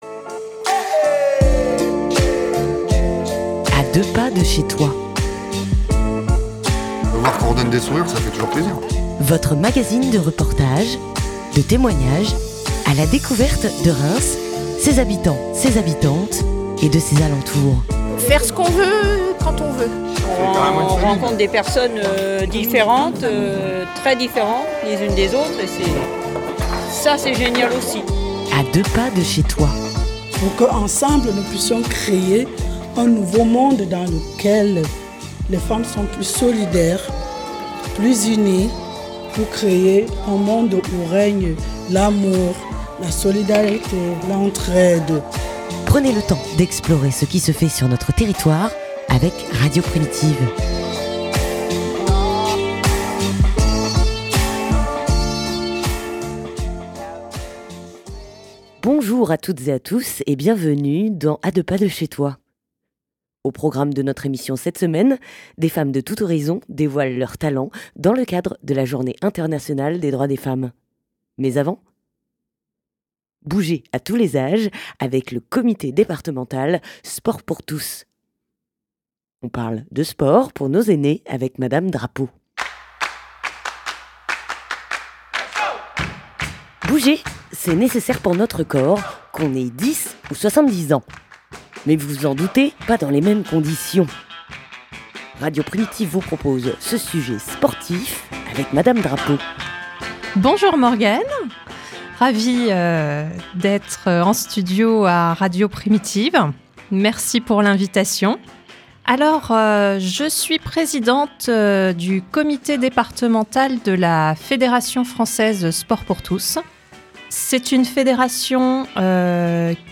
( de 00 : 00 à 17 : 00 ) Nous vous emmenons ensuite en reportage au CROUS de Reims qui accueillait cette semaine l'association Femmes Relais 51 et ses partenaires pour un évènement autour de la Journée Internationale des Droits des Femmes .